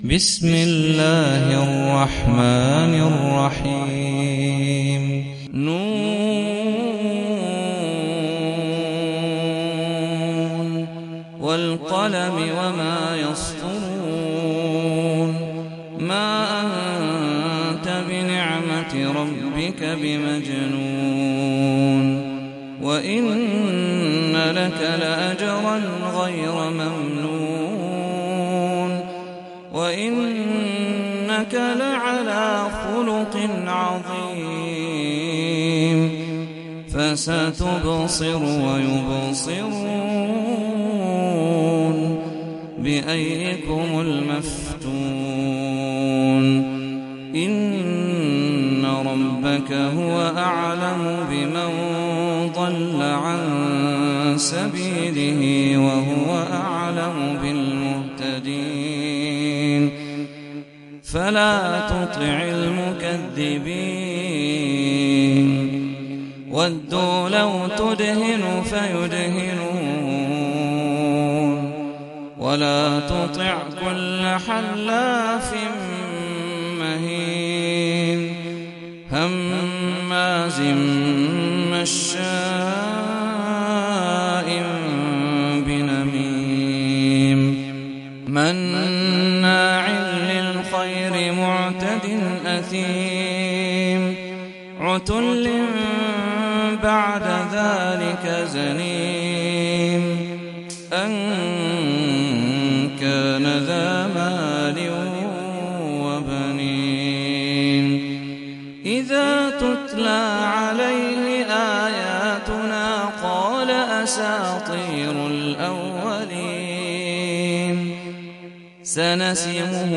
سورة القلم - صلاة التراويح 1446 هـ (برواية حفص عن عاصم)
جودة عالية